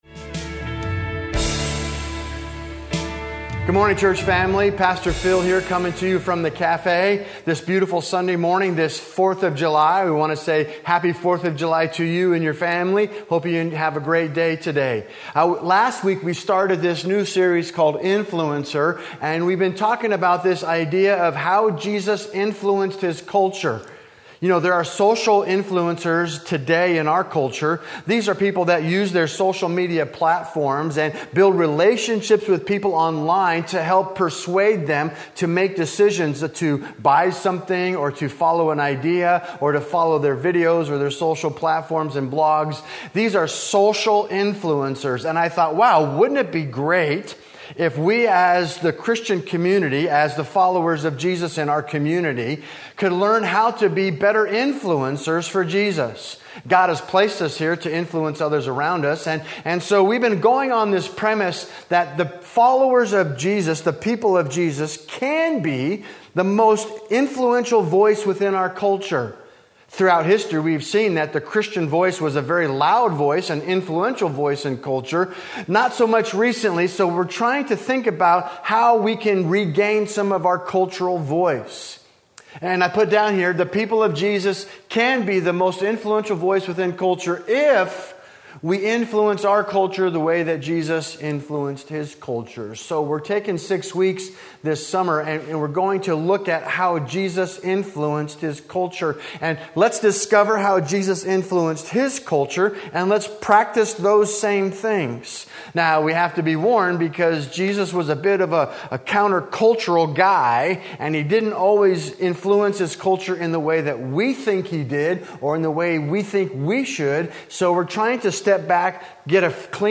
A message from the series "Influencer."